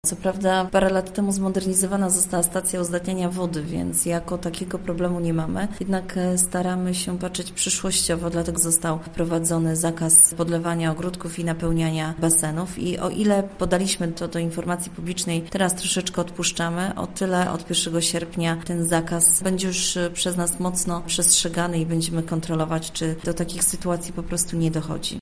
– Bardziej niż na zagrożenie karą administracyjną liczę na zdrowy rozsądek mieszkańców – mówi Katarzyna Kromp, wójt gminy Tuplice: